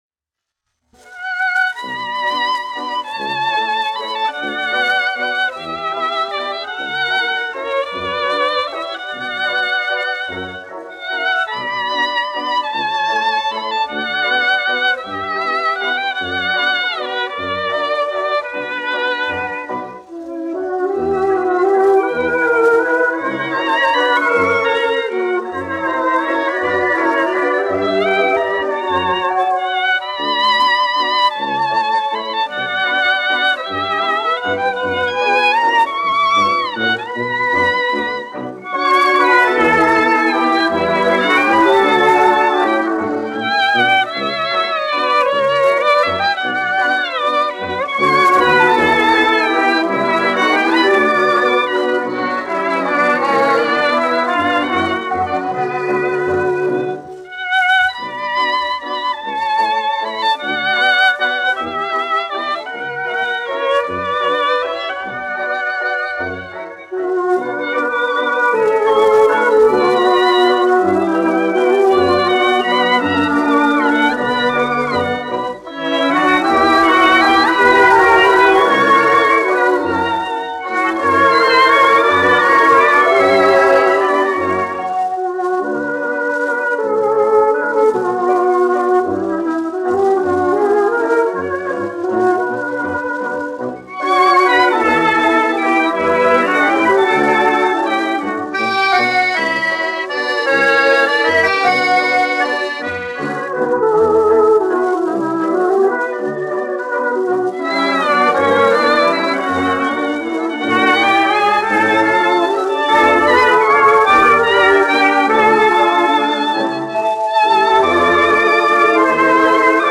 1 skpl. : analogs, 78 apgr/min, mono ; 25 cm
Populārā instrumentālā mūzika
Skaņuplate
Latvijas vēsturiskie šellaka skaņuplašu ieraksti (Kolekcija)